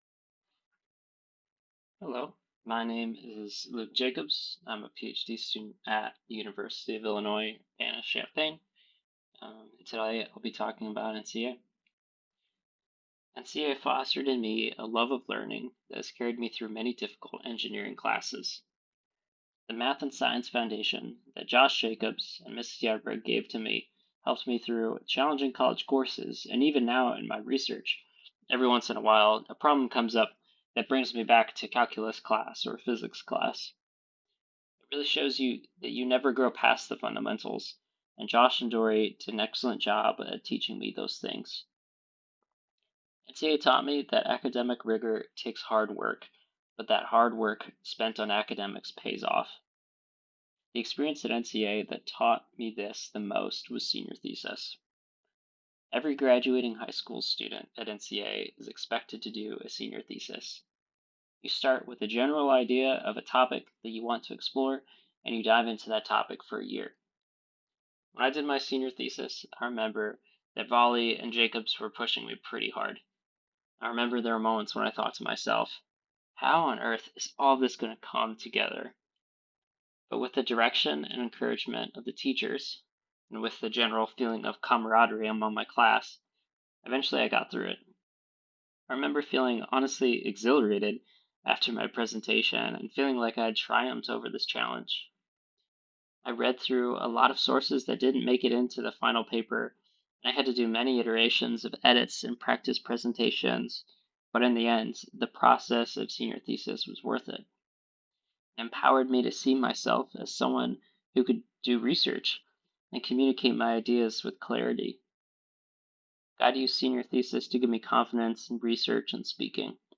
Listen below to speeches given at NCA’s 25th Year Celebration Gala about how these alumni were shaped by their NCA education and teachers.